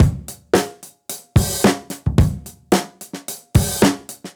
Index of /musicradar/dusty-funk-samples/Beats/110bpm
DF_BeatD_110-02.wav